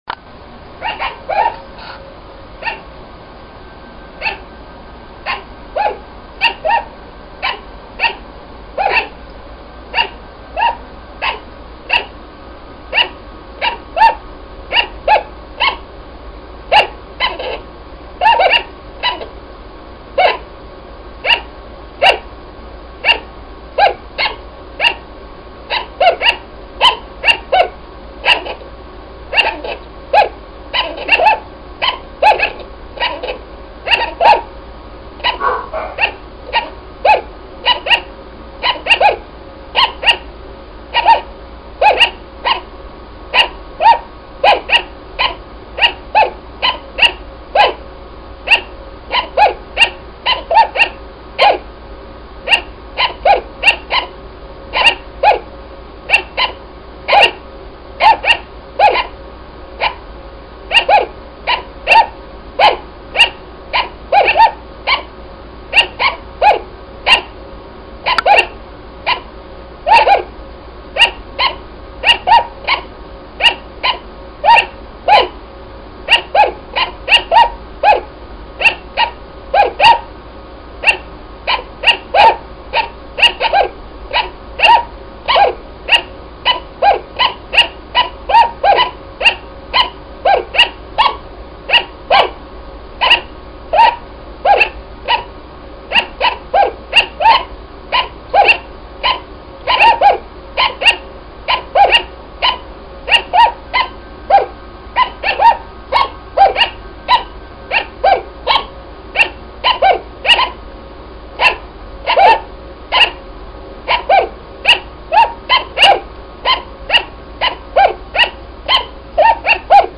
Nut House cage barking after a storm
I recorded three minutes of the Nut House 5 sugar glider cage barking after a storm passed over. It is just the single cage and I believe you can hear four of them in this segment.